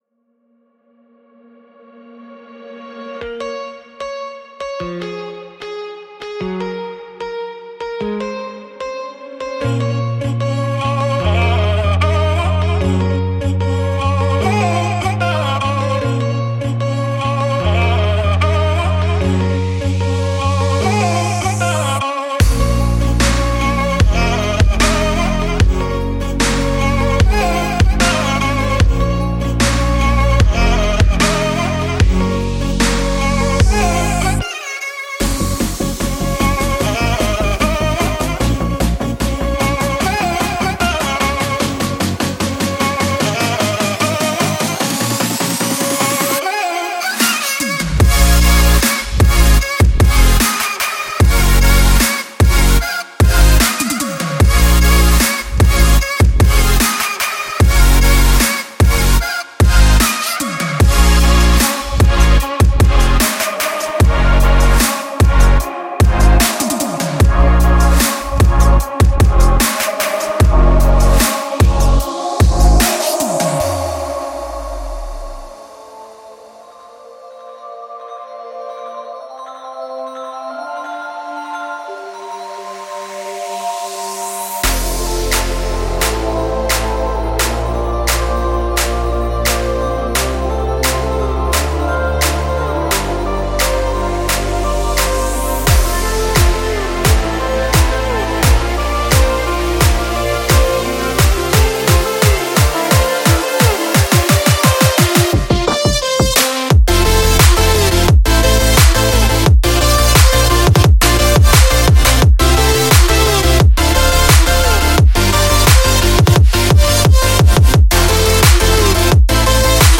鼓的种类繁多。
未来的低音鼓非常特别。